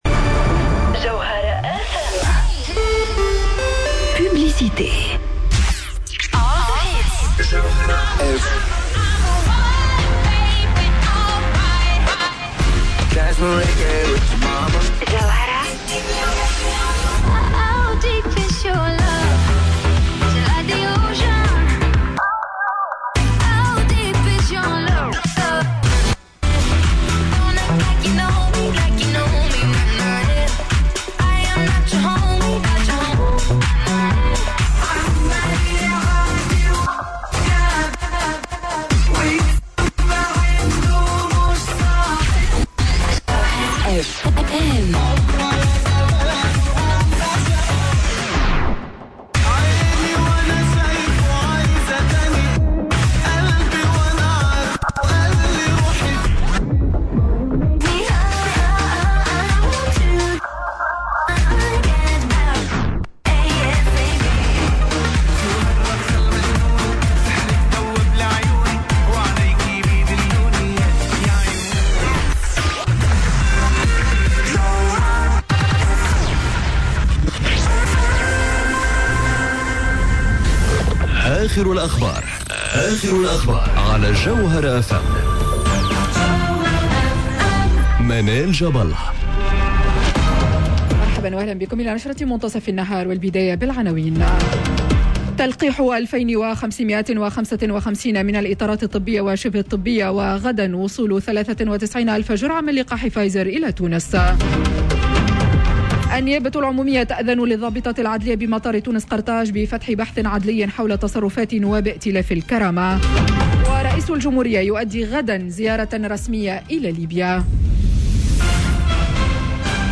نشرة أخبار منتصف النهار ليوم الثلاثاء 16 مارس 2021